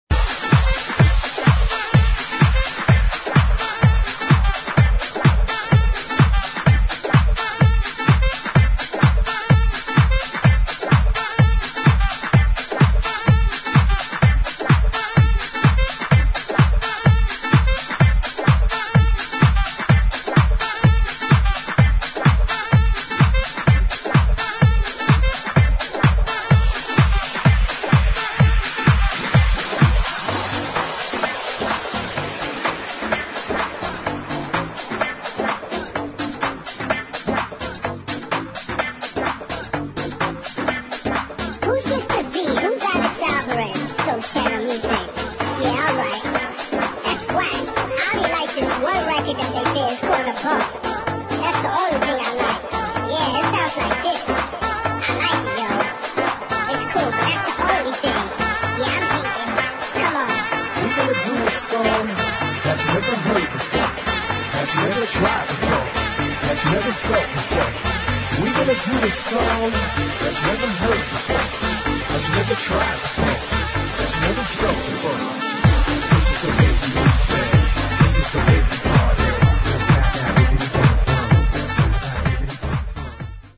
[ HOUSE | HIP HOUSE | FUNKY HOUSE ]